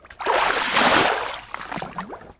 water-splash-3.ogg